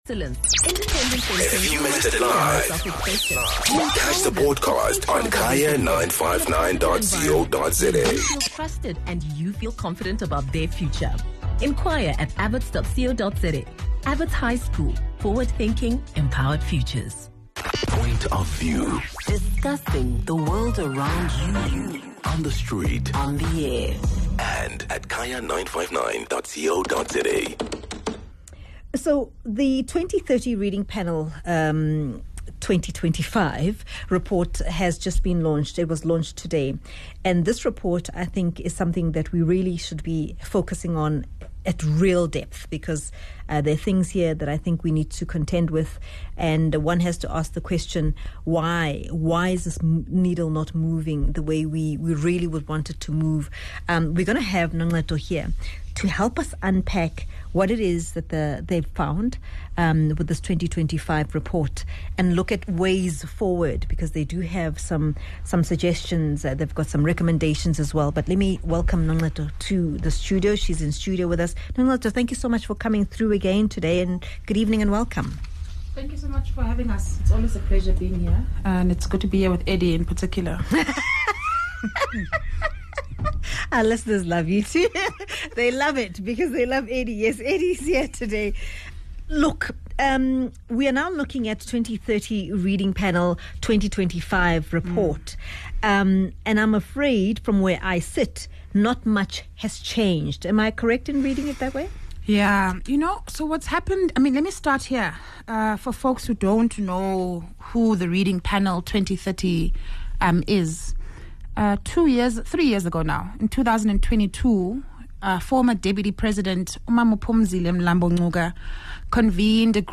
25 Feb Discussion: National Urgency to Transform Early-Grade Reading